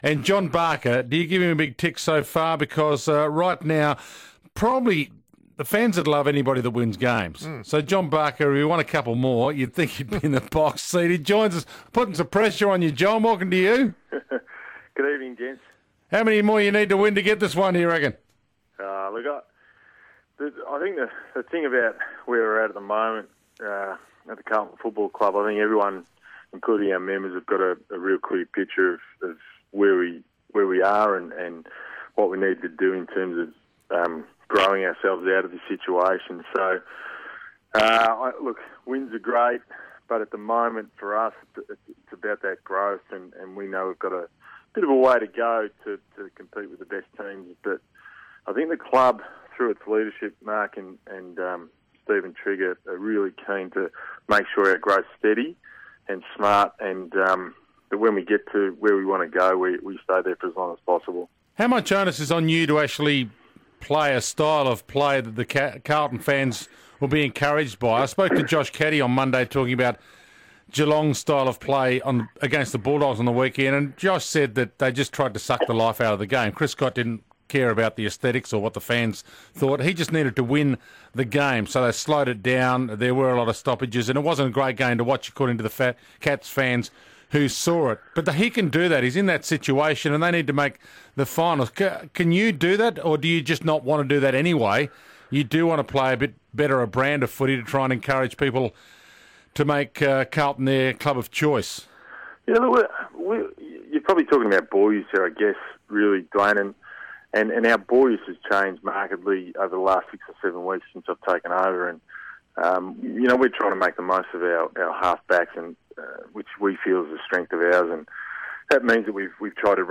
speaks to 3AW's Sports Today ahead of the Round 17 clash against Hawthorn.